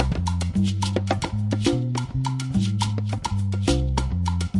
80年代迪斯科鼓乐循环播放 114 bpm
描述：80年代迪斯科鼓圈。使用Roland TR505创建。
标签： 牛铃 环路 混响 的PERC 80年代 节奏 复古 罗兰 质朴 舞蹈 迪斯科 复古 节拍 叩诊环 量化 鼓环 常规
声道立体声